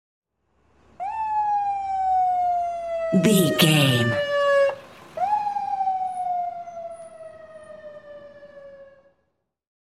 Ambulance Ext Passby Arrive Siren 76
Sound Effects
urban
chaotic
emergency